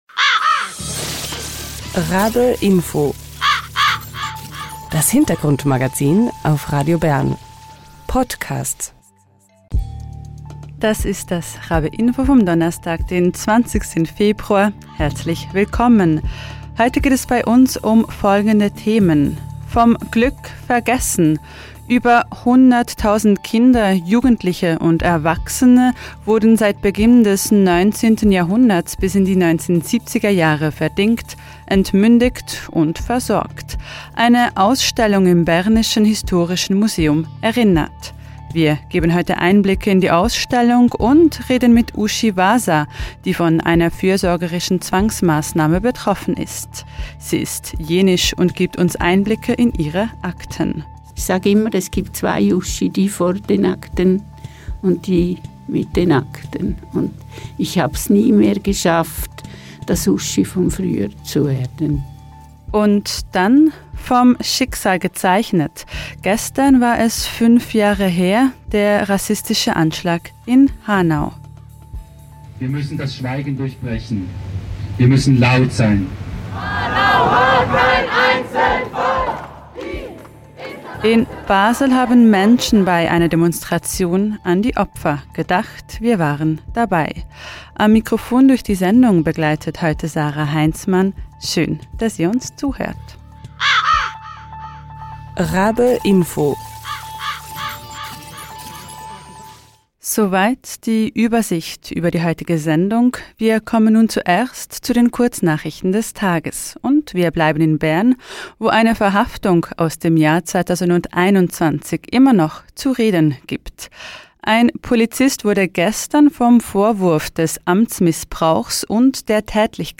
Sie gedenken bei einer Demonstration durch die Basler Innenstadt an die Opfer des rechtsextremen und rassistisch motivierten Anschlags. RaBe-Info sendet die Eindrücke von der gestrigen Demonstration.